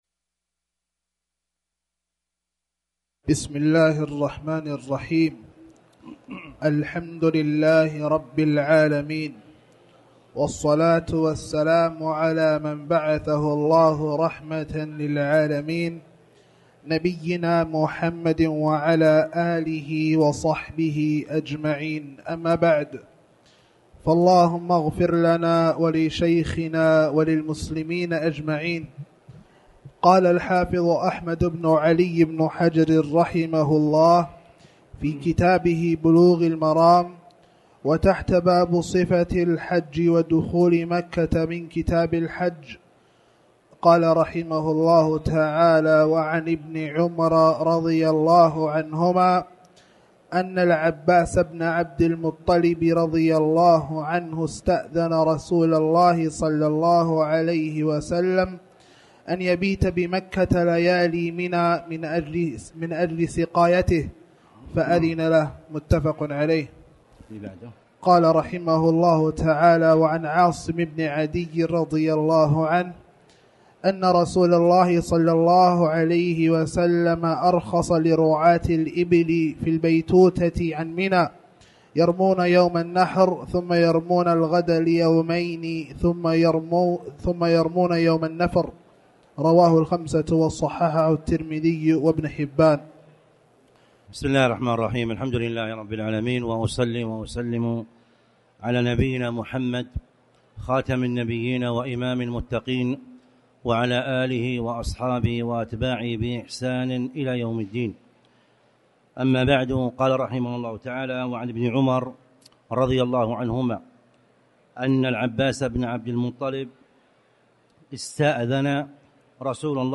تاريخ النشر ٤ ذو القعدة ١٤٣٨ هـ المكان: المسجد الحرام الشيخ